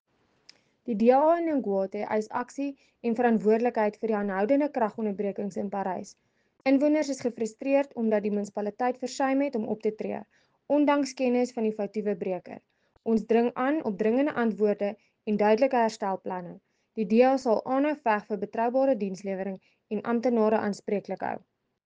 Afrikaans soundbites by Cllr Marié la Cock and